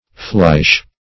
Search Result for " flysch" : The Collaborative International Dictionary of English v.0.48: Flysch \Flysch\ (fl[=e]sh), n. [A Swiss word, fr. G. fliessen to flow, melt.]